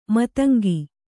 ♪ matangi